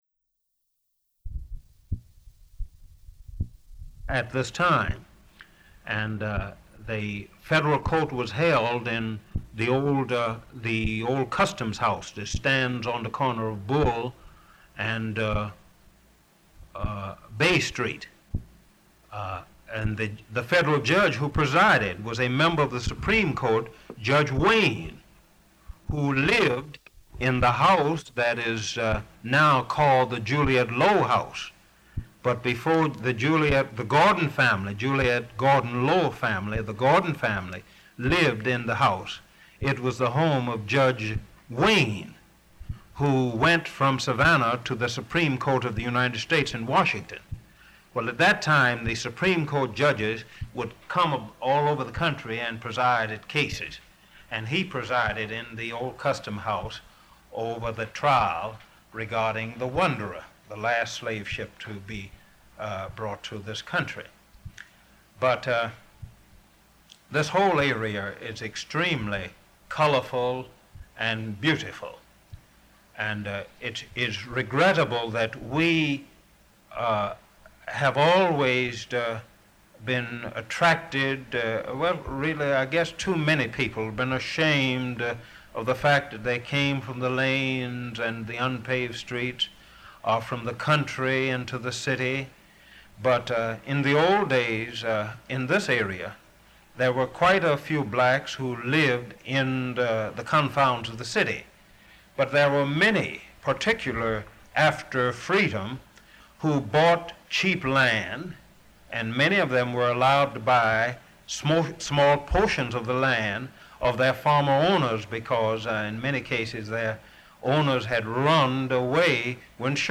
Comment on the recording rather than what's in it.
Tape, Magnetic